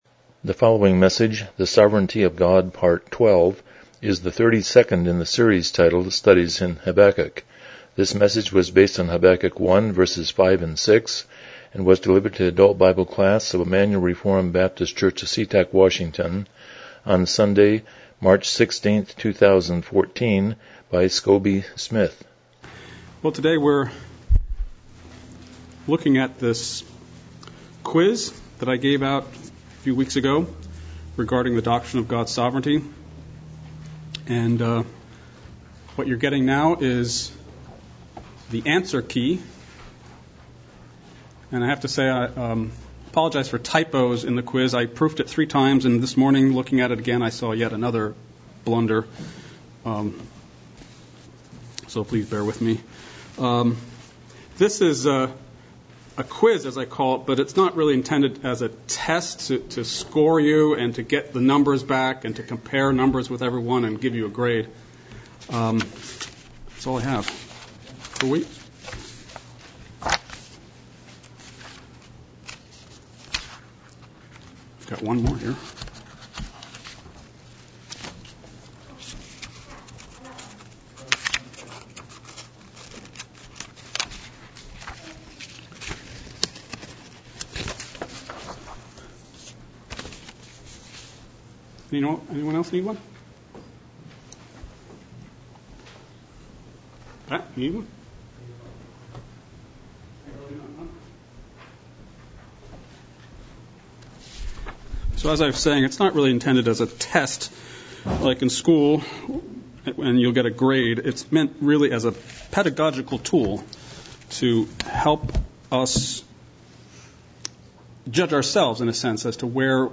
Studies in Habakkuk Passage: Habakkuk 1:5-6 Service Type: Sunday School « 48 The Sermon on the Mount